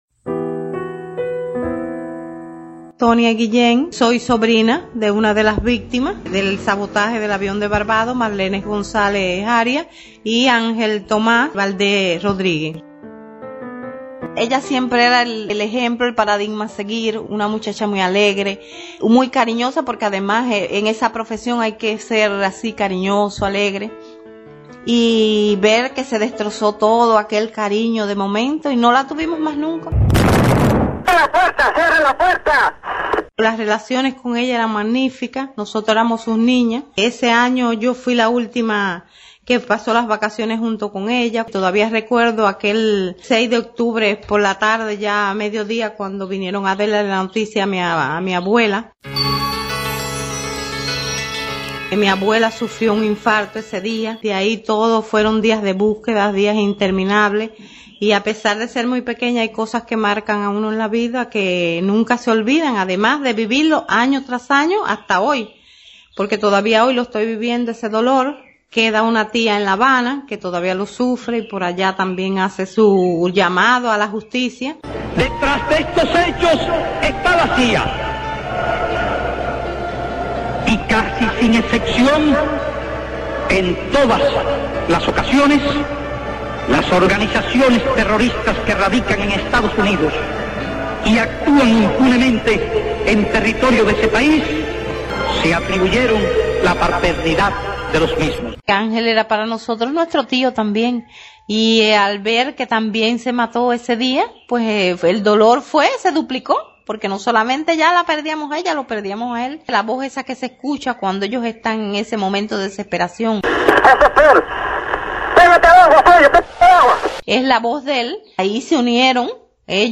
Testimonio.mp3